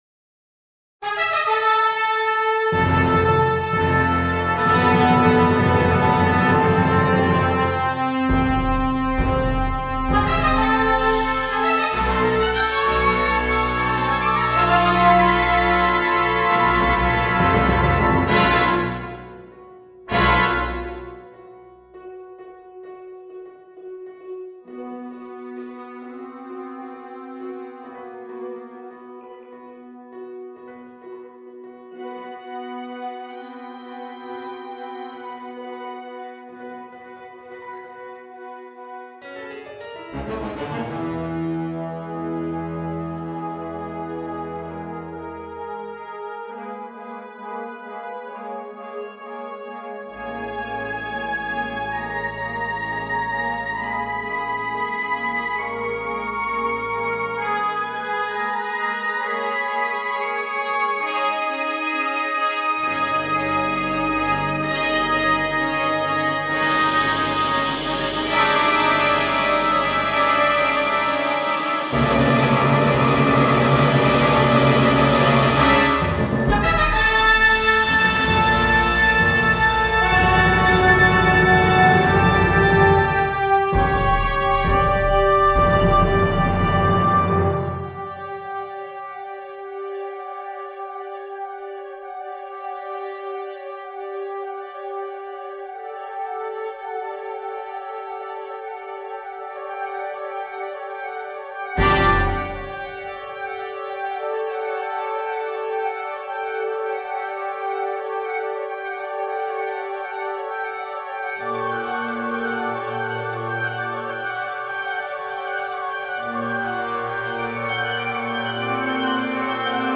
Suite für Blasorchester